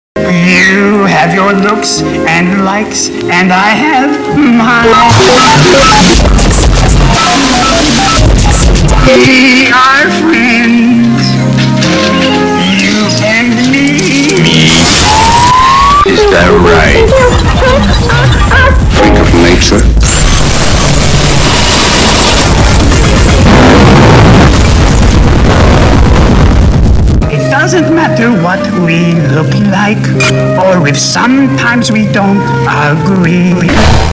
a remix rip off of a dictionaraoke version